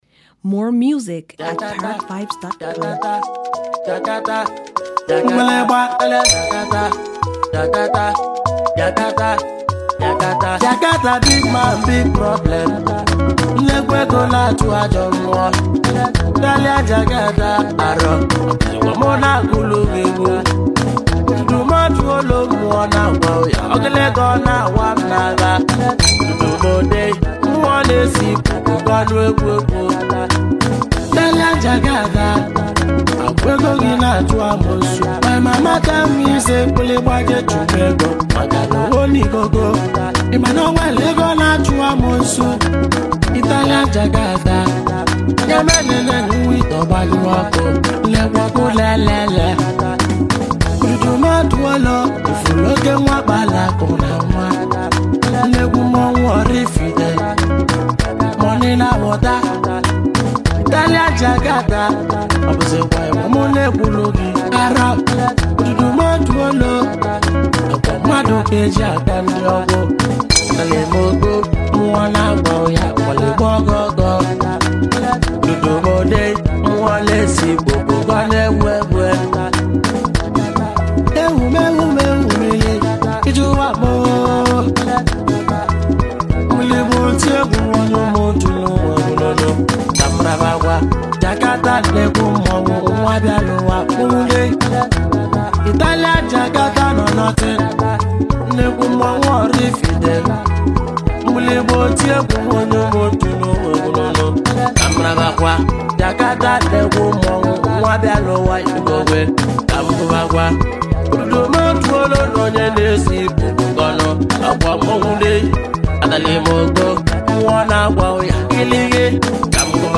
Well-known Gifted Nigerian singer-songwriter
HIGHLIFE